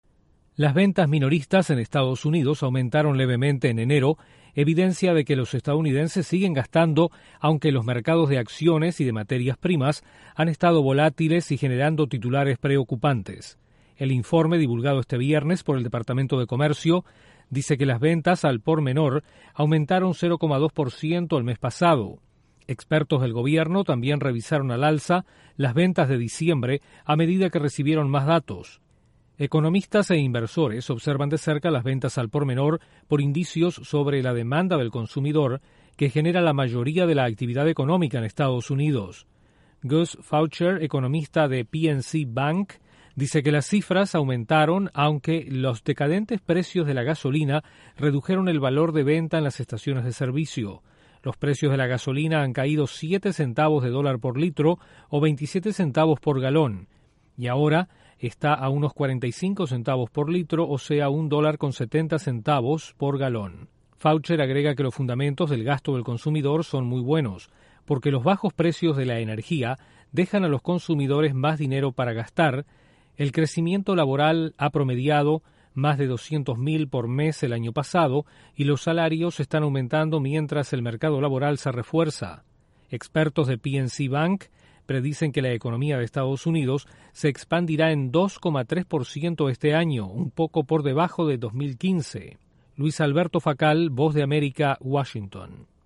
Las ventas al por menor en EE.UU. aumentaron, mientras los mercados accionarios han estado volátiles. Desde la Voz de América en Washington